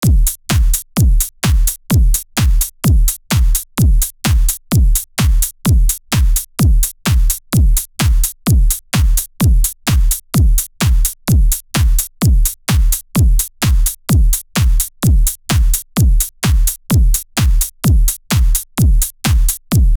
Генерация музыки по текстовому запросу.
Пример генерации для запроса 128 BPM, techno drum music